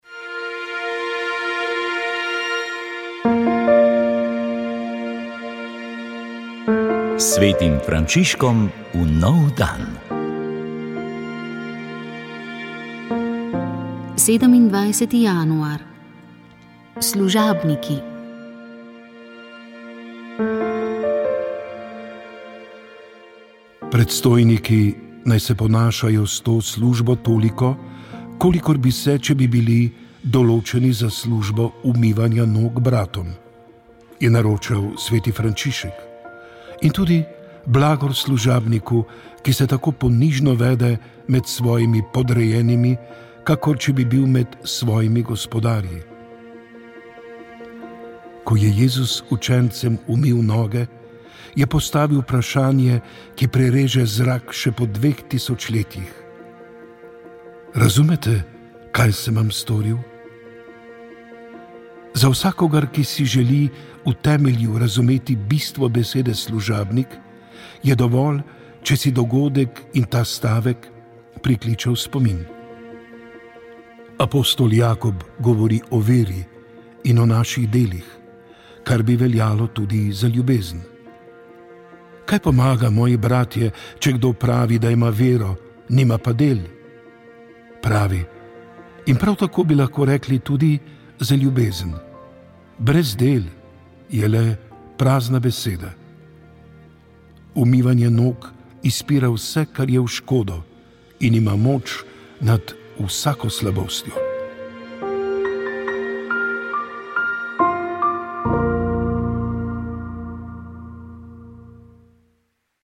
Vremenska napoved 26. januar 2022